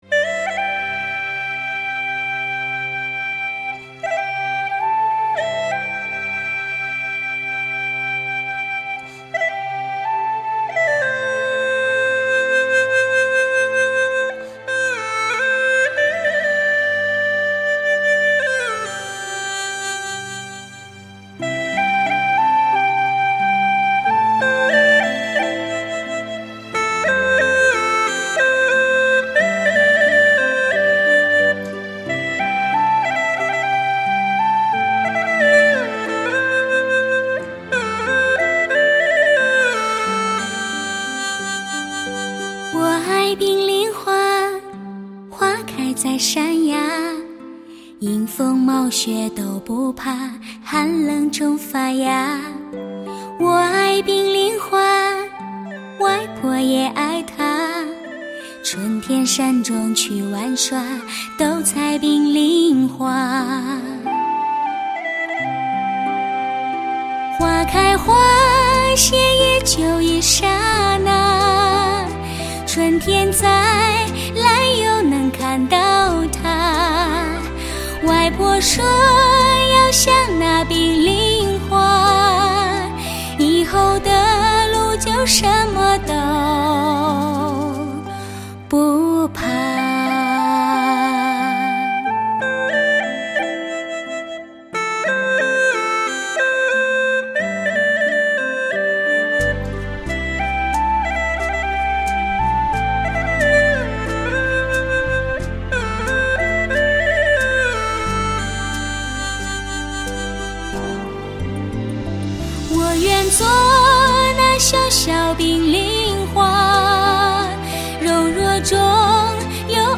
甜润的发烧
独一无二的甜润原色